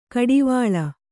♪ kaḍivāḷa